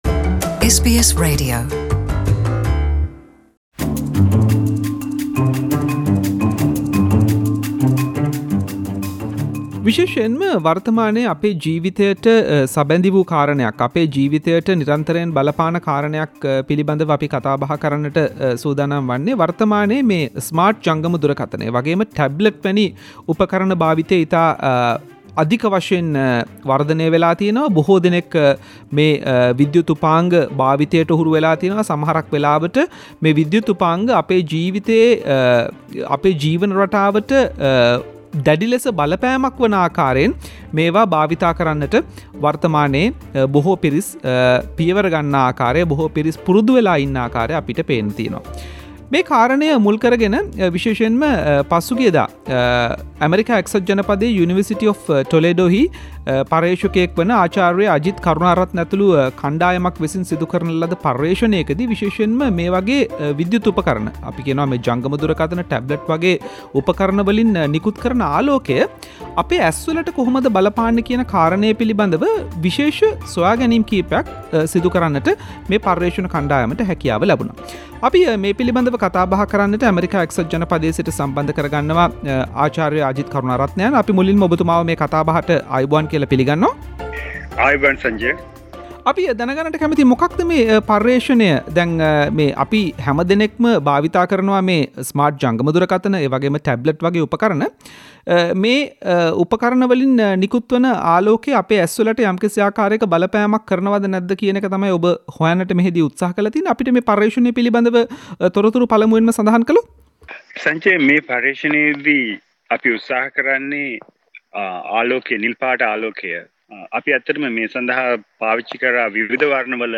SBS සිංහල වැඩසටහන කල කතා බහක්.